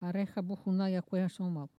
Enquête Arexcpo en Vendée